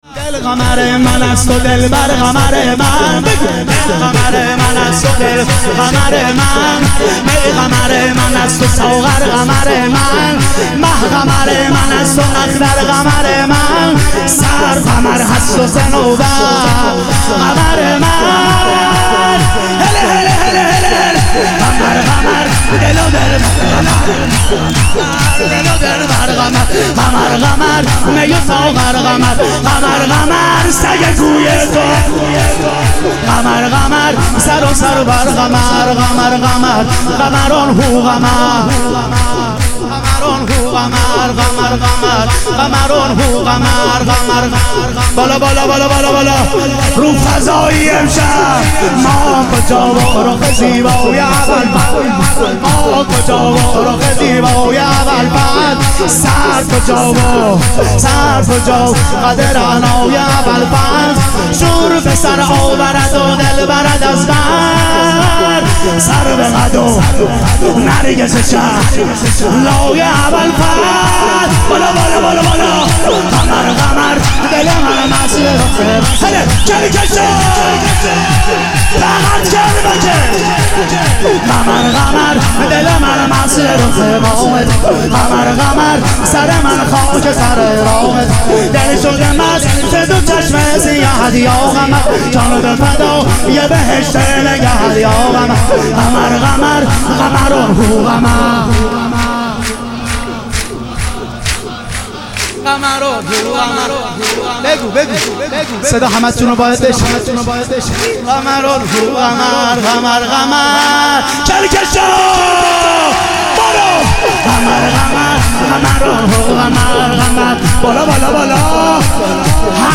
ظهور وجود مقدس حضرت عباس علیه السلام - شور